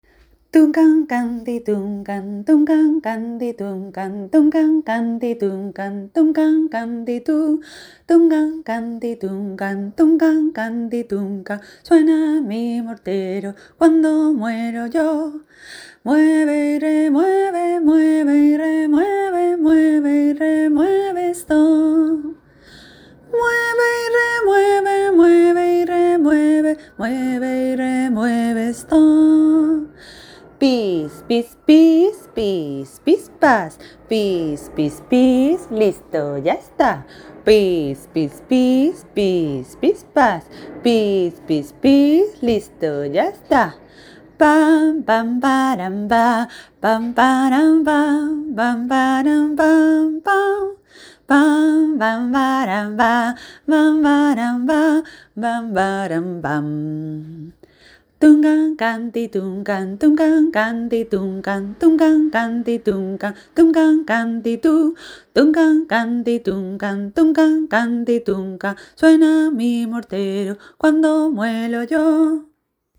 Mayor y doble.